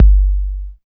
59 808 KICK.wav